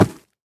Minecraft Version Minecraft Version snapshot Latest Release | Latest Snapshot snapshot / assets / minecraft / sounds / mob / piglin / step5.ogg Compare With Compare With Latest Release | Latest Snapshot
step5.ogg